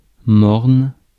Ääntäminen
IPA: [mɔʁn]